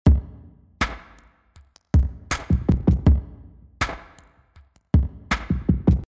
Den Beat von Cinematique Instruments Marble finde ich inzwischen nun doch etwas zu spitz.